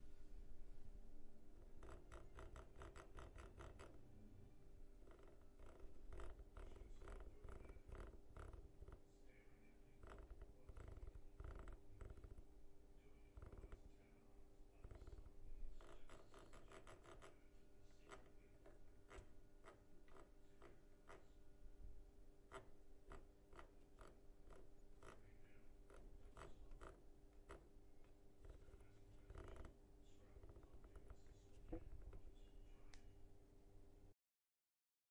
桑拿 " 桑拿闲置
描述：一个电热桑拿房缓慢地呼啸着，发出嘶嘶声、啪啪声、咔嚓声，最近抛出的水慢慢地沸腾起来，冷却了这堆热石和金属底盘。
Tag: 点击 嘶嘶声 流行 桑拿